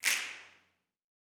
SNAPS 03.wav